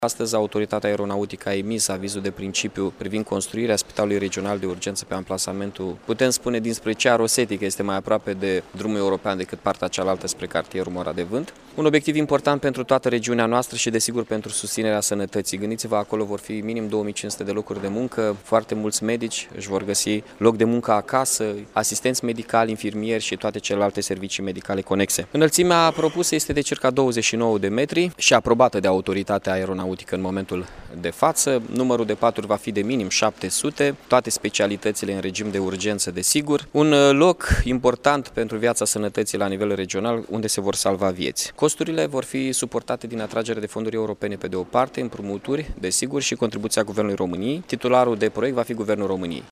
Mihai Chirica:
Anunţul a fost făcut de primarul Iaşului, Mihai Chirica, în cadrul unei conferinţe de presă.